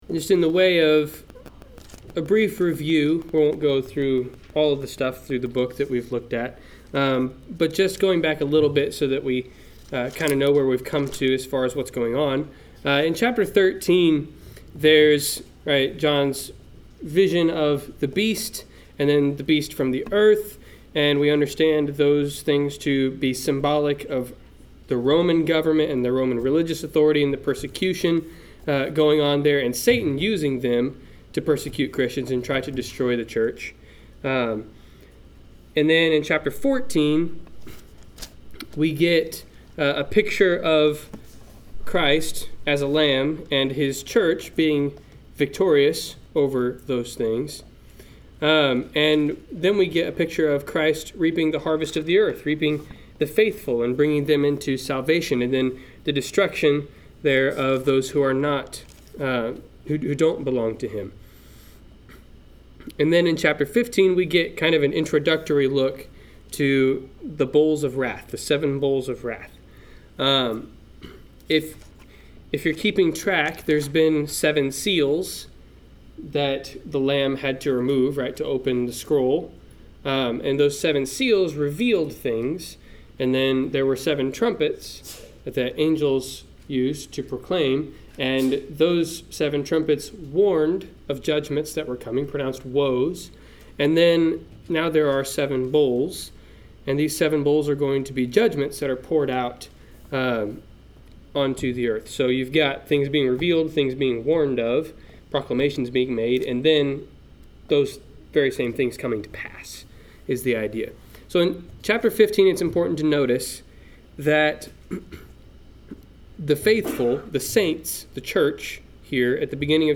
Service Type: Wednesday Night Class